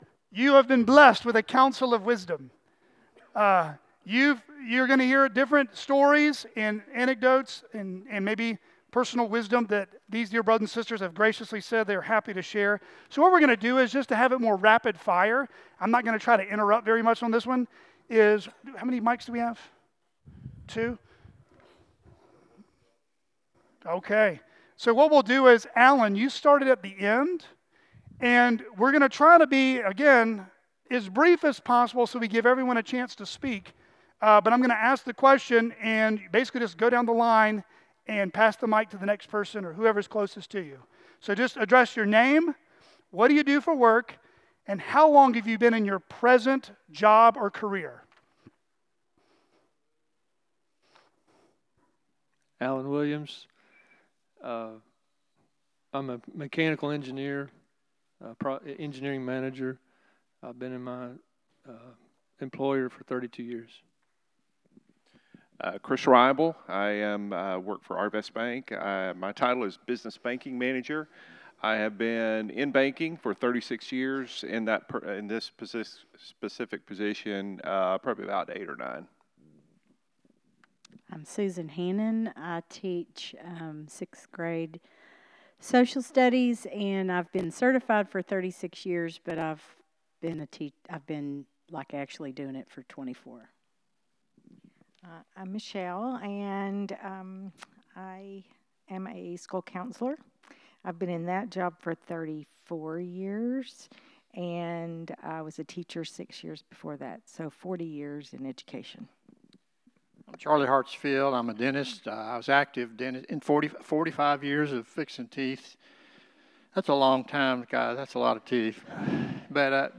Vocational Q&A Panel